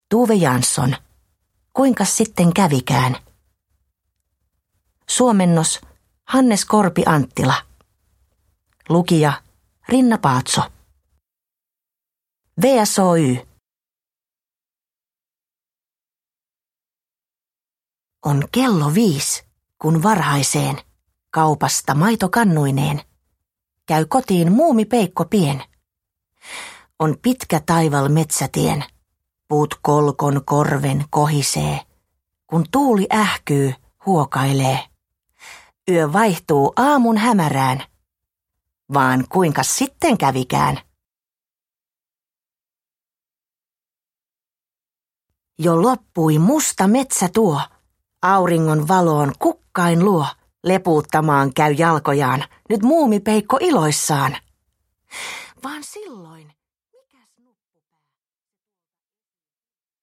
Kuinkas sitten kävikään? – Ljudbok – Laddas ner
Lastenkamarin klassikko, Tove Janssonin kertoma ja kuvittama iki-ihana kuvakirja nyt myös äänikirjana!